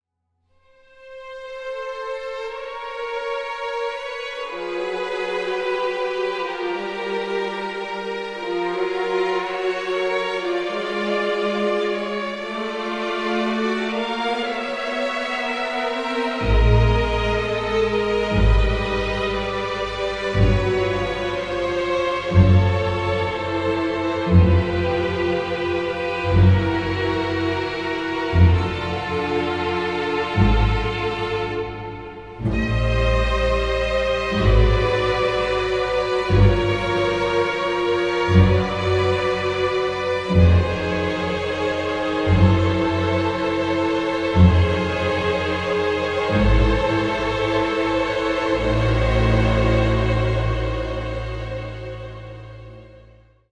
a sultry and suspenseful score